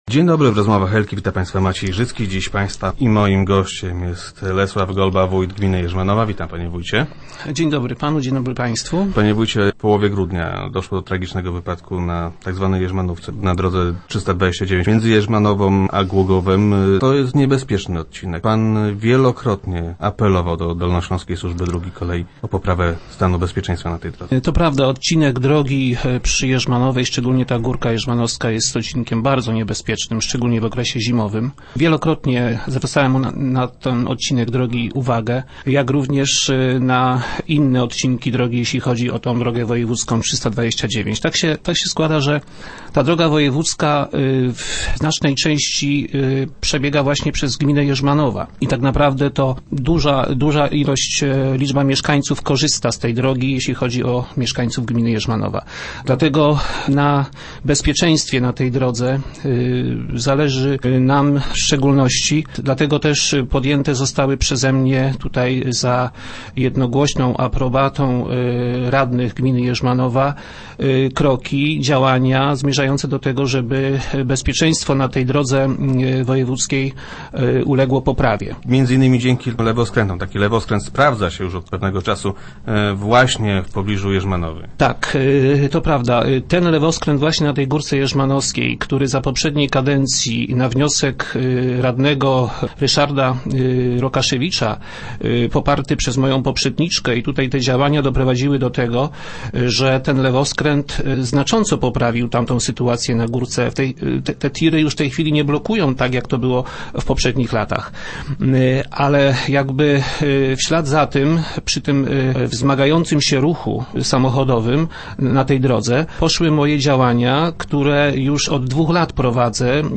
Sytuację mają poprawić lewoskręty do Jaczowa, Smardzowa i Bądzowa. - Powinny one powstać w tym roku – twierdzi Lesław Golba, wójt gminy Jerzmanowa.
Już w budżecie na miniony rok mieliśmy zabezpieczone pieniądze na dofinansowanie przebudowy tych skrzyżowań - mówił na radiowej antenie Lesław Golba.